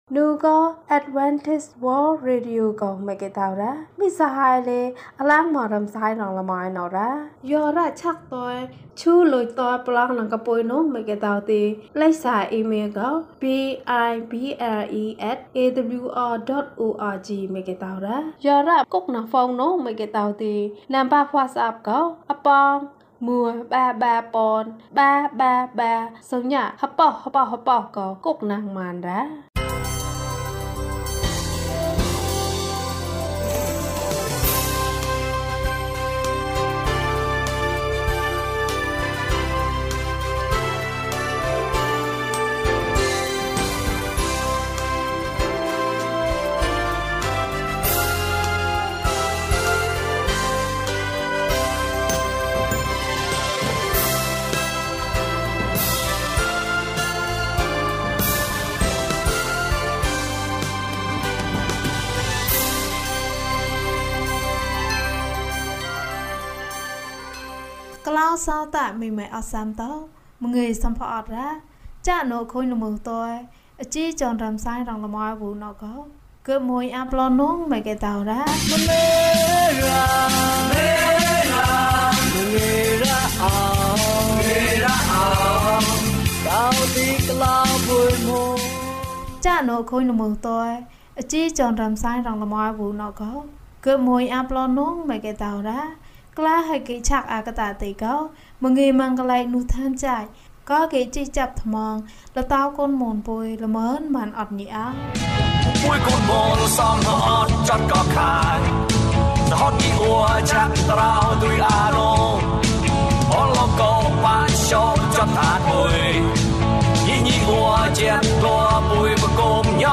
မင်္ဂလာရှိသောအိမ်။ ကျန်းမာခြင်းအကြောင်းအရာ။ ဓမ္မသီချင်း။ တရားဒေသနာ။